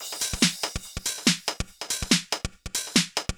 Index of /musicradar/uk-garage-samples/142bpm Lines n Loops/Beats
GA_BeatA142-12.wav